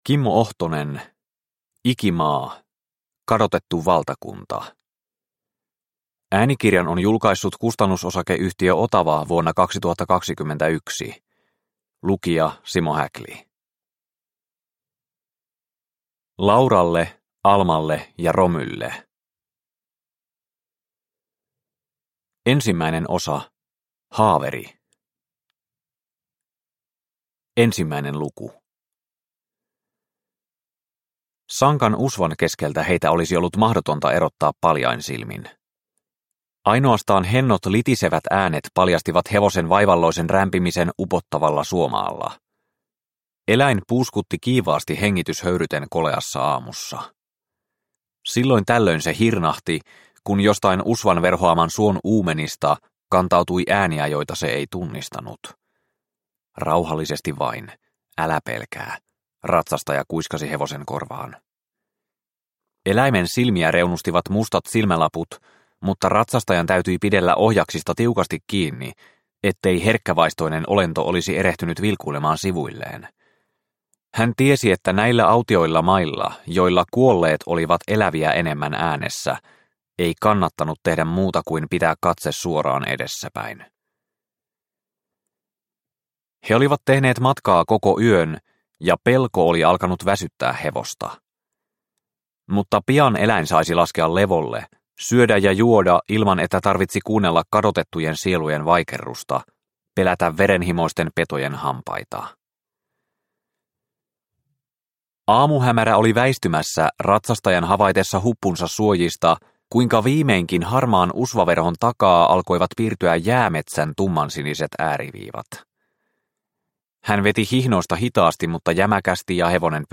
Ikimaa - Kadotettu valtakunta – Ljudbok – Laddas ner